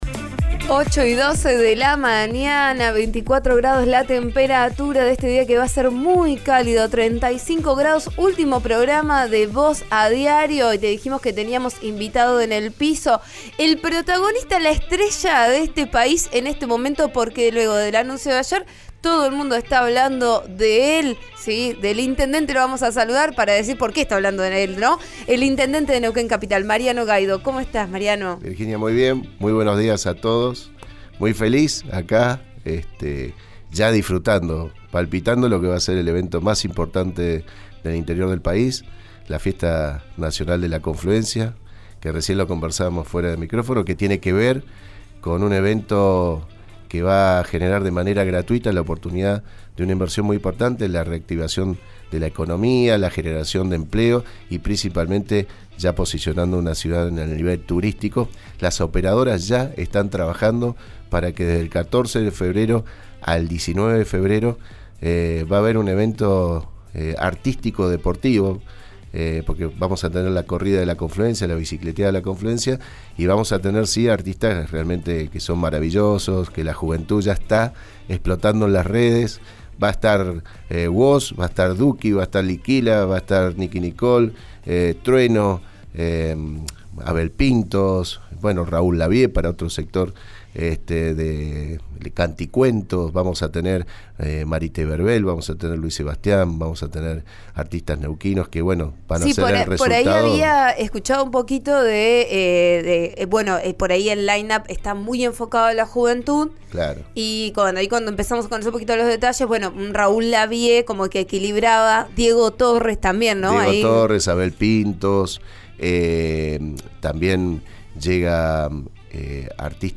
La tan esperada noticia la dio el intendente Mariano Gaido en RN Radio.